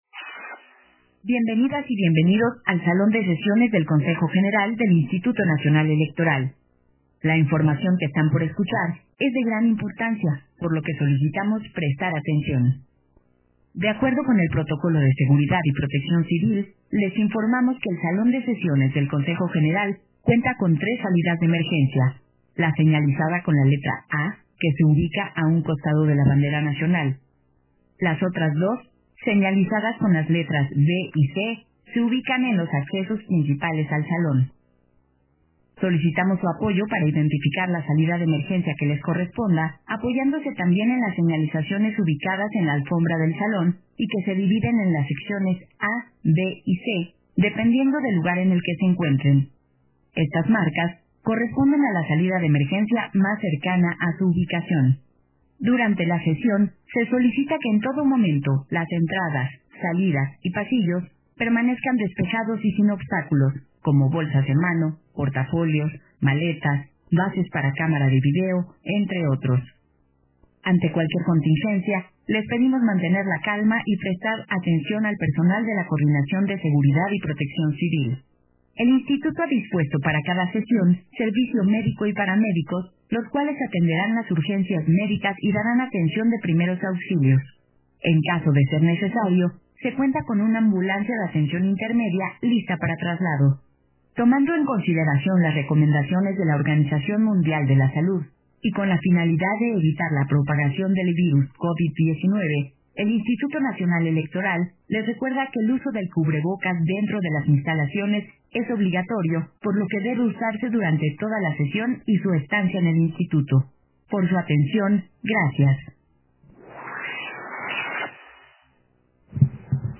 «DR0000_4687.mp3» de TASCAM DR-05.
Versión estenográfica de la conferencia de prensa, que ofrecieron las y los Consejeros Electorales, previo a la Consulta Popular 2021